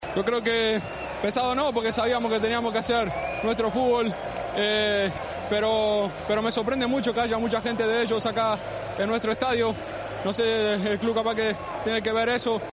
El central habló tras el partido en el micrófono de Movistar e instó al club a que investigara por qué había tantos aficionados del Eintracht en el Camp Nou.